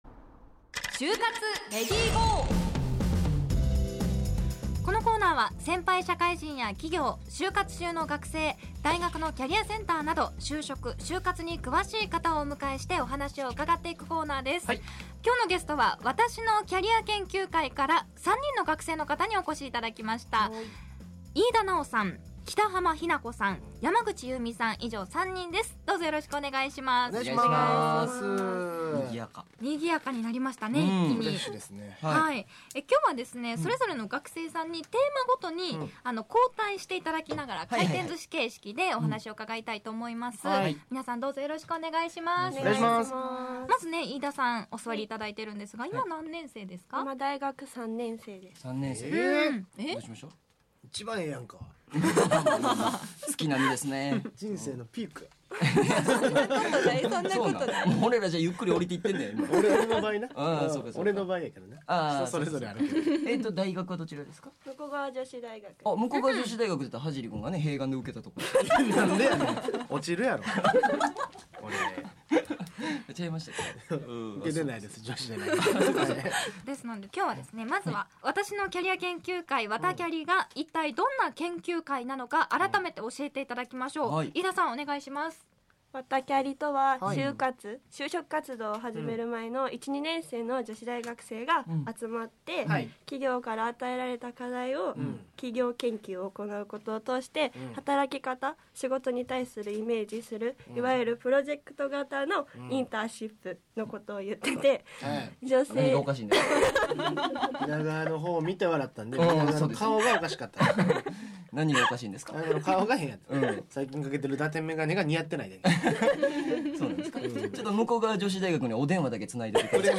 『ネイビーズアフロのレディGO！HYOGO』2020年7月3日放送回（「就活レディGO！」音声）